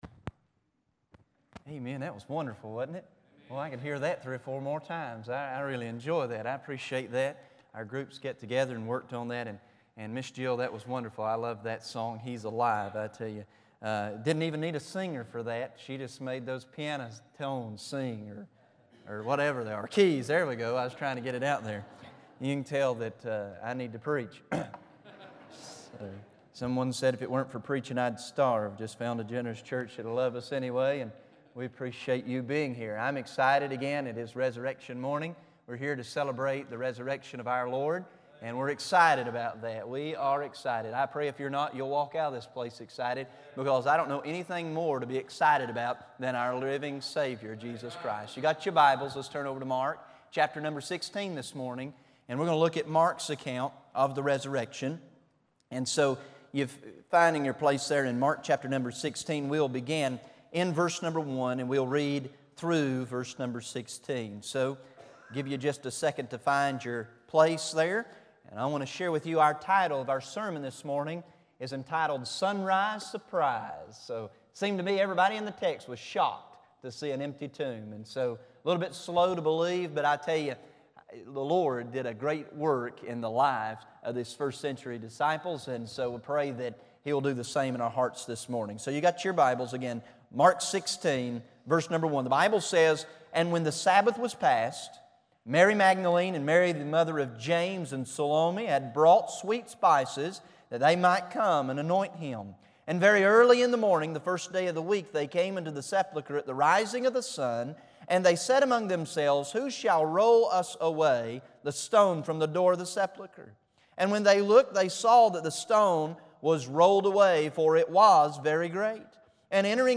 4-4-10 am easter sermon.mp3